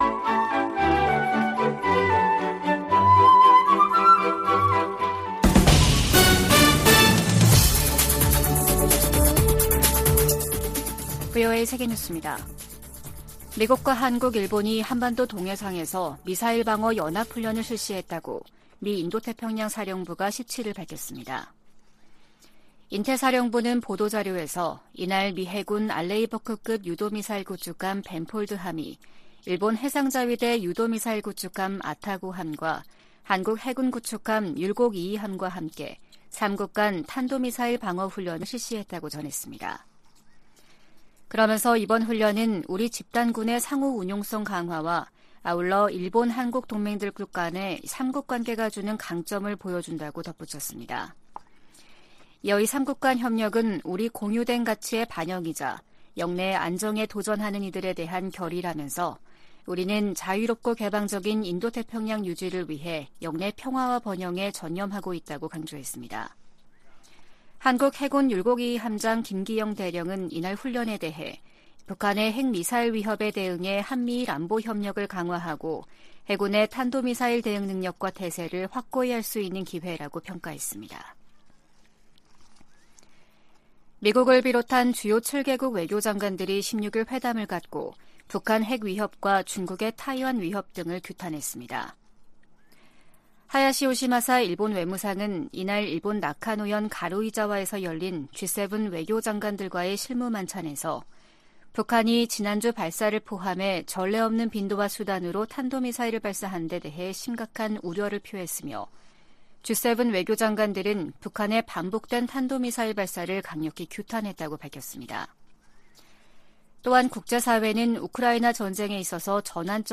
VOA 한국어 아침 뉴스 프로그램 '워싱턴 뉴스 광장' 2023년 4월 18일 방송입니다. 한국과 일본의 외교·국방 당국이 참여하는 '2+2' 외교안보대화가 서울에서 5년만에 열렸습니다. 미한일이 3년 만에 안보회의를 개최하고 미사일 방어와 대잠수함전 훈련 정례화에 합의했습니다. 유엔 안보리가 17일, 북한의 대륙간탄도미사일(ICBM) 발사에 대응한 공개 회의를 개최합니다.